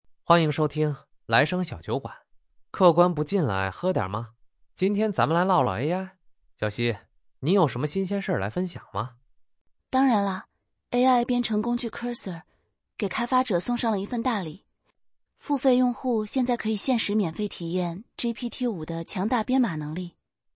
doubaoTTS.wav